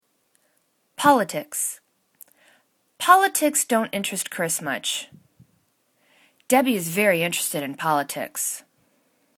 pol.i.tics    /'politiks/    n
politics.mp3